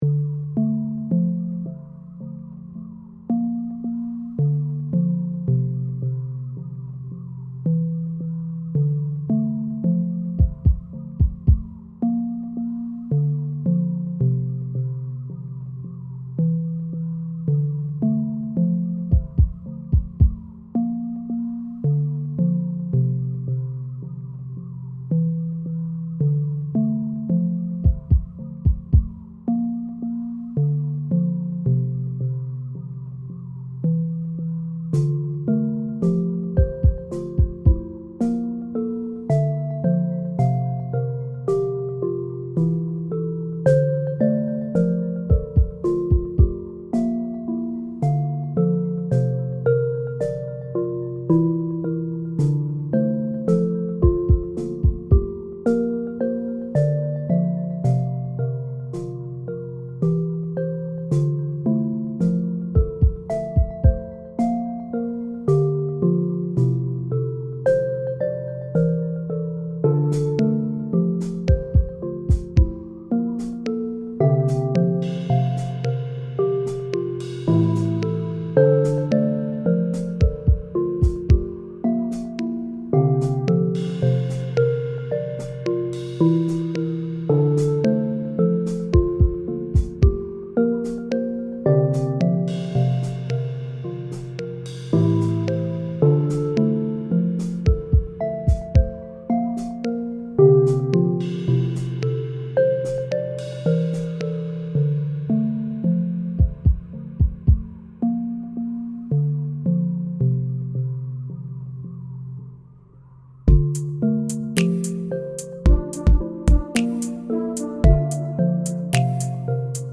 Currently browsing: Lofi